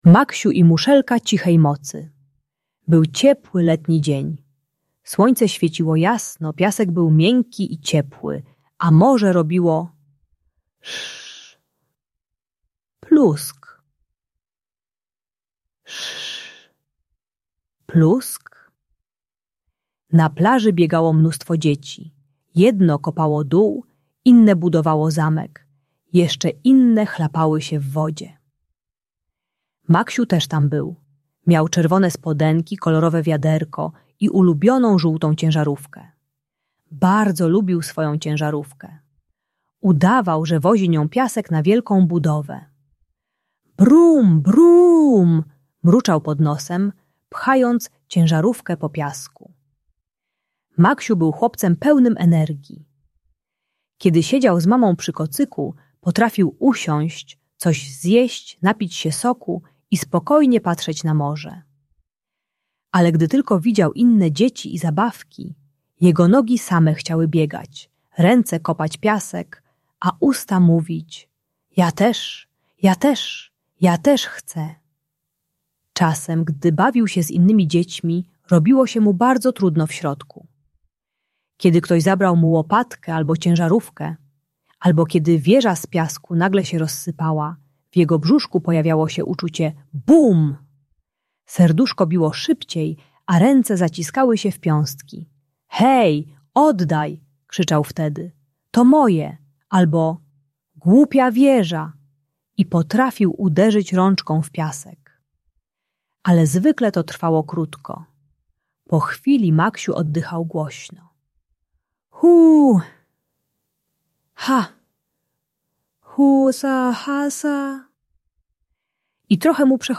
Historia Maksia uczy techniki "Trzech Oddechów Morza" - jak uspokoić się przed reakcją, używać spokojnych słów zamiast krzyku i dawać sobie chwilę na ochłonięcie. Darmowa audiobajka o radzeniu sobie ze złością i agresją.